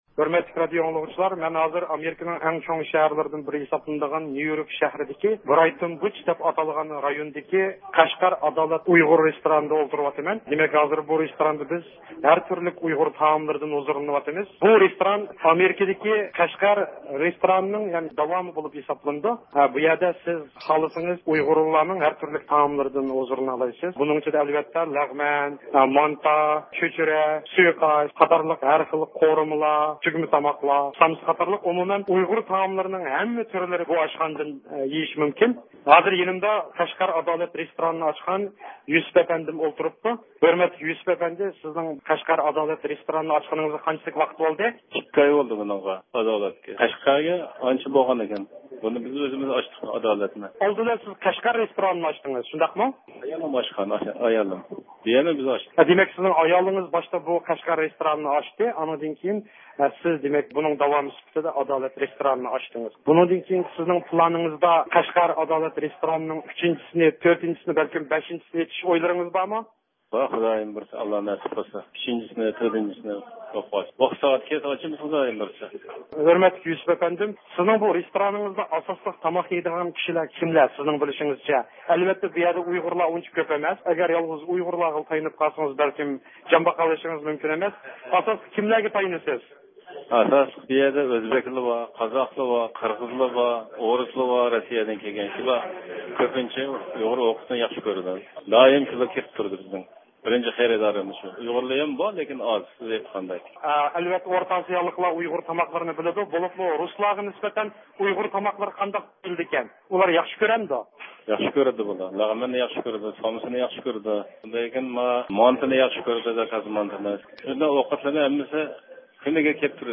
نەق مەيداندىن بەرگەن سۆھبىتىنى ئاڭلايسىلەر.